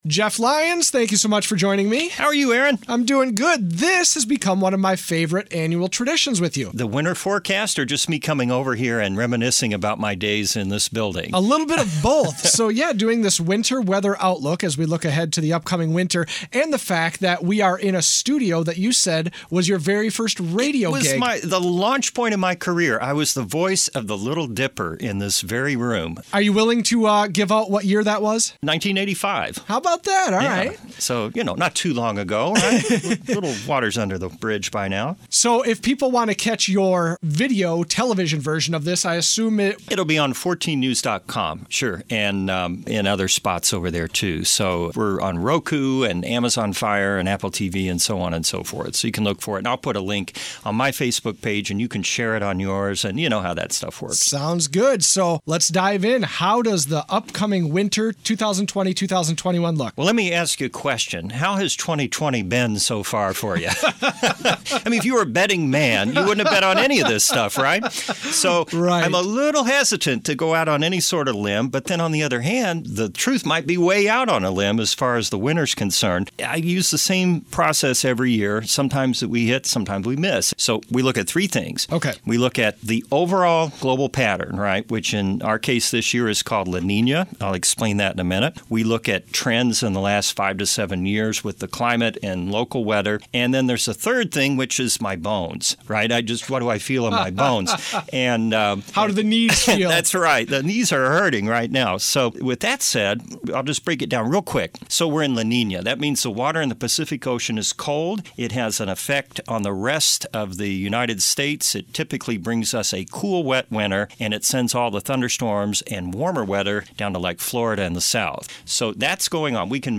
Adult Contemporary Radio